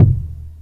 FeelDuckBoom.wav